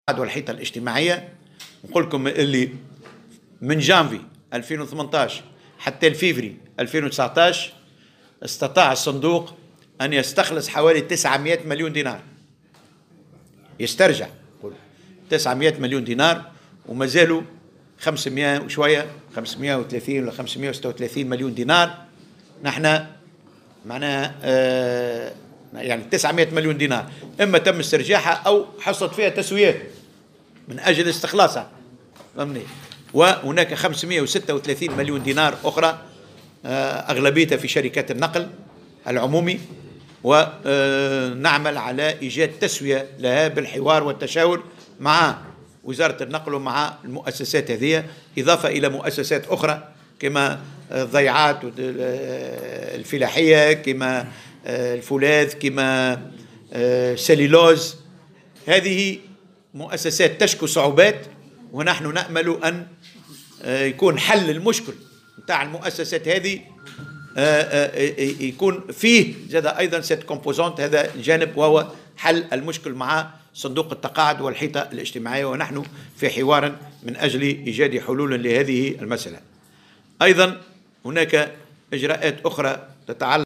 قال محمد الطرابلسي وزير الشؤون الاجتماعية في تصريح لمراسل الجوهرة "اف ام" خلال ندوة صحفية عقدها صباح اليوم إن صندوق التضامن والحيطة الاجتماعية تمكن من استرجاع 900 مليون دينار من المؤسسات بين جانفي 2018 و فيفري 2019.